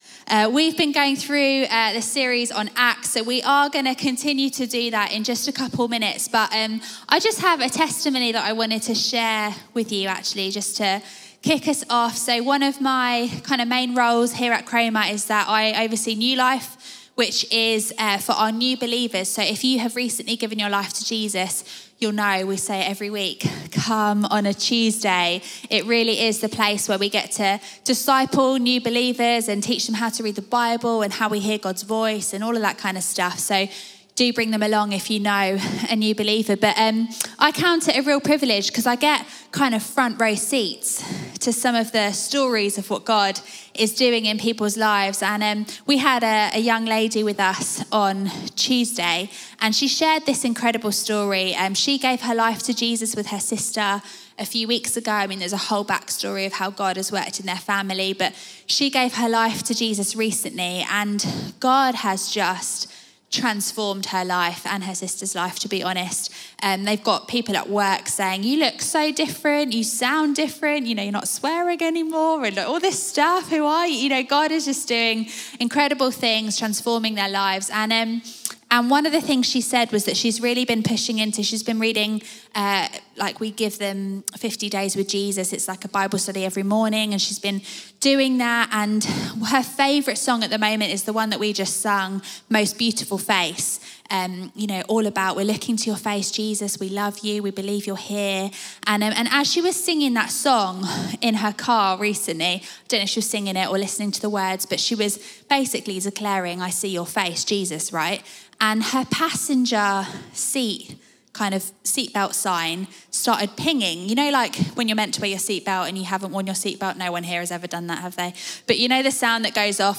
Sunday Sermon Christ in YOU, The Hope Of Glory